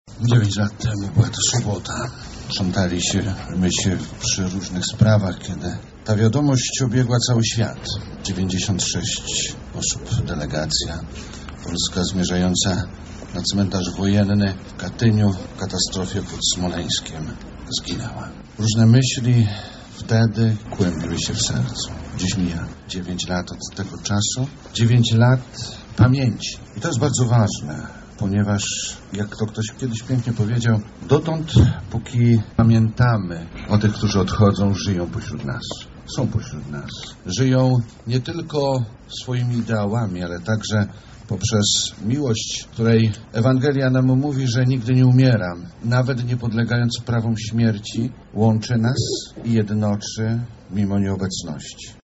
Ceremonia była kontynuowana na cmentarzu przy ulicy Lipowej. Nie zabrakło też rozważań.